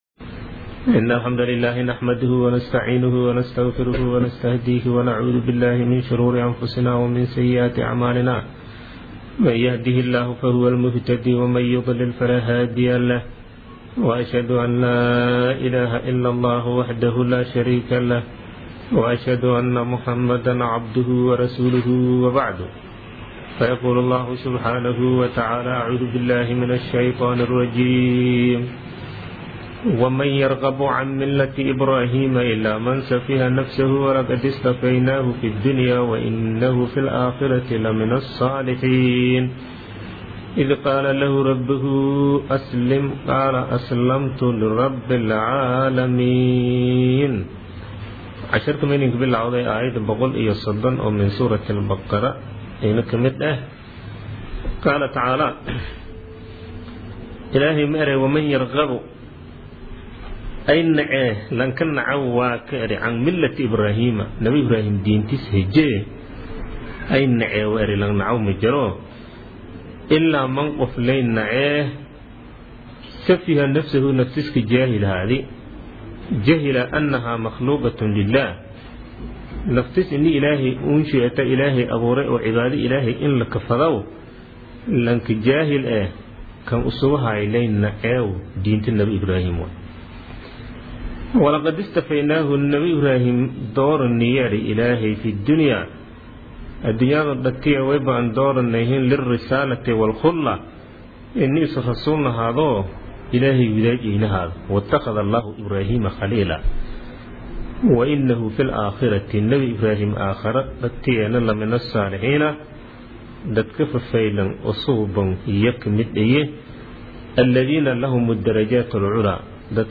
Casharka Tafsiirka Maay 16aad